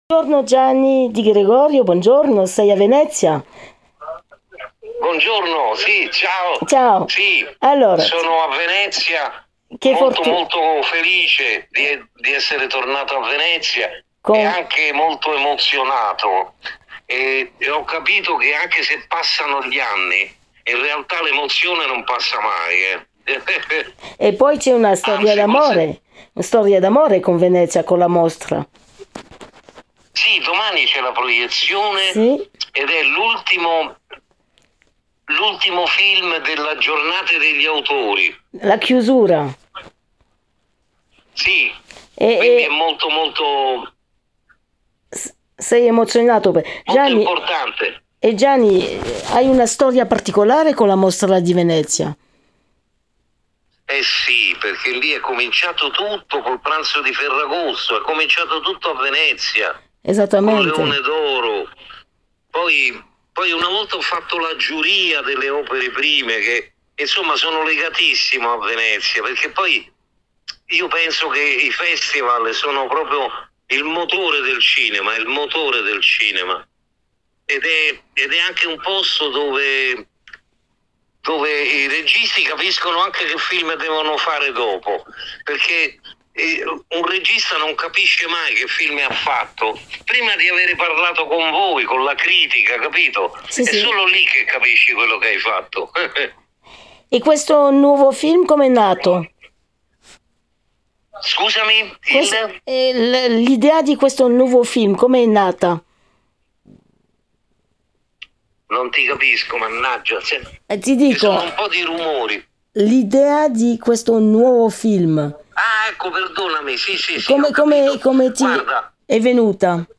Mostra 2025 - Giornate degli Autori: Gianni Di Gregorio torna al Lido di Venezia per presentare Come ti muovi, sbagli. Intervista - j:mag